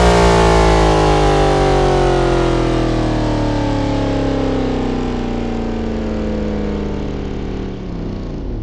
rr3-assets/files/.depot/audio/Vehicles/v8_09/v8_09_Decel.wav
v8_09_Decel.wav